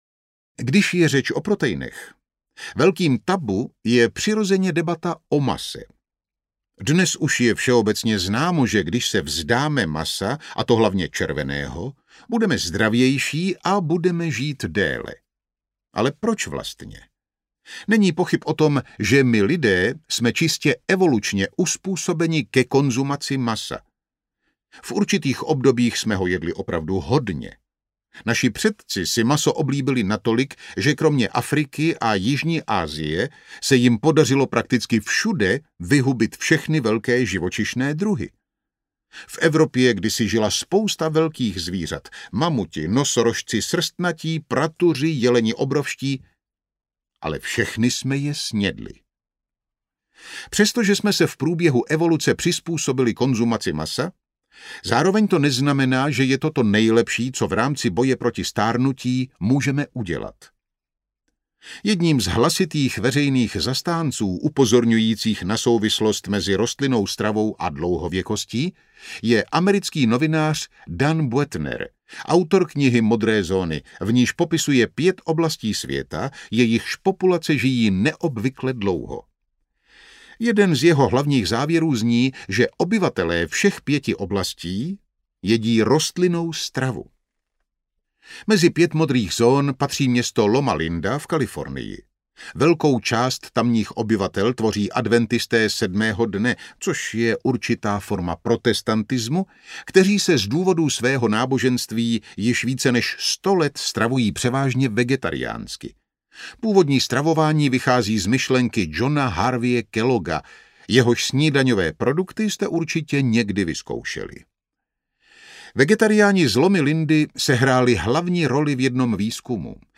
Medúzy stárnou pozpátku audiokniha
Ukázka z knihy
Vyrobilo studio Soundguru.